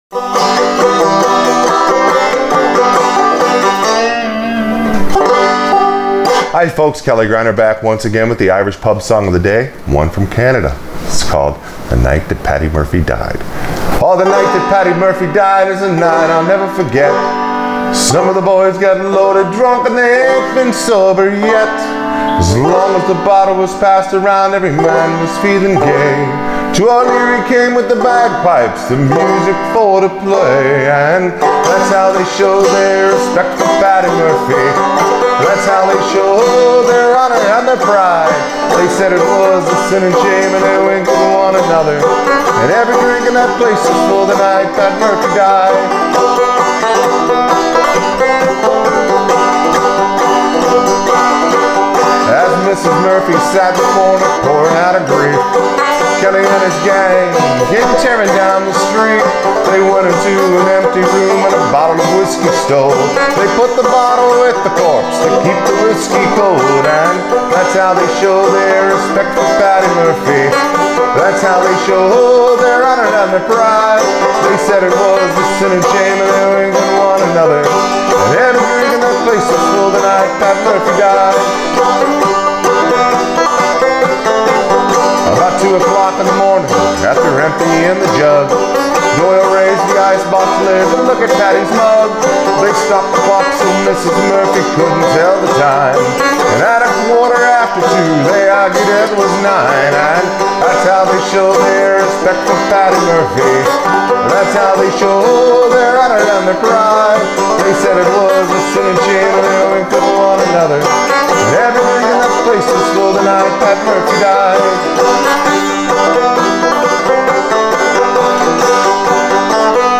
I love playing this song on Frailing Banjo. It’s not so much about playing the melody but having fun while playing the chords. A pull-off, a hammer-on and some slides, make it fun!